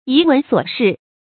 遺聞瑣事 注音： ㄧˊ ㄨㄣˊ ㄙㄨㄛˇ ㄕㄧˋ 讀音讀法： 意思解釋： 見「遺聞逸事」。